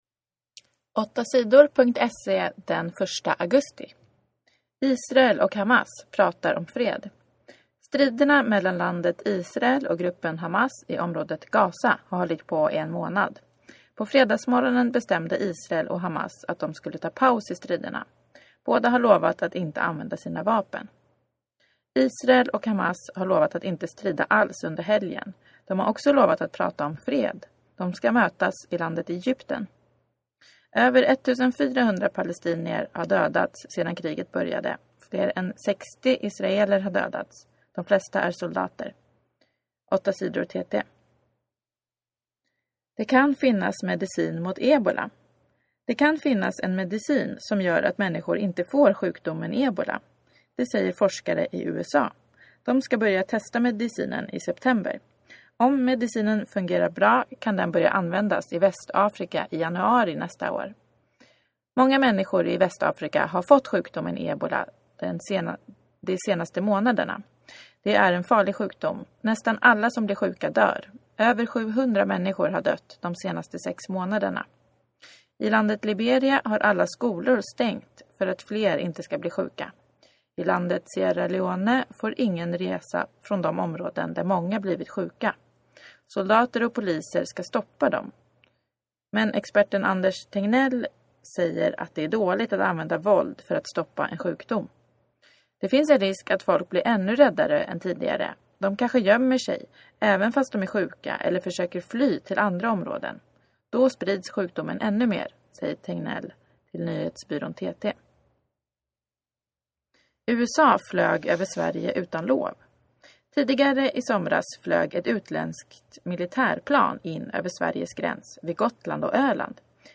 Inlästa nyheter den 1 augusti